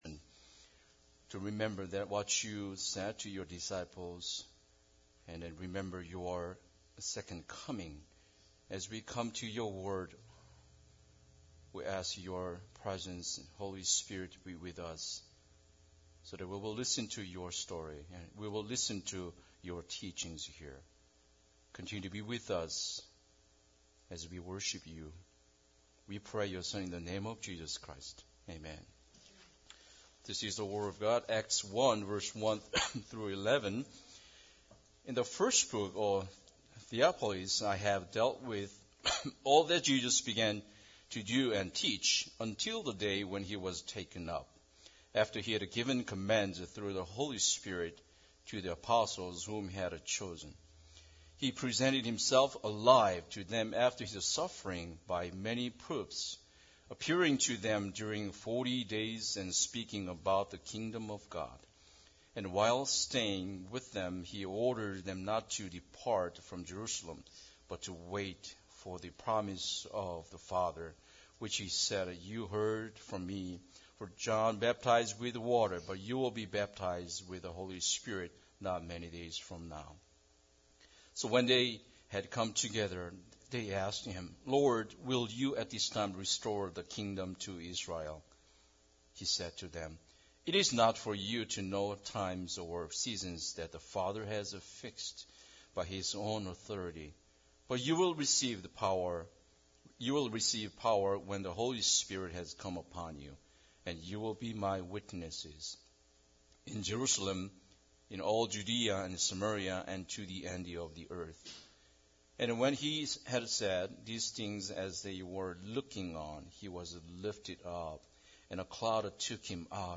Ascension Day Service – 2014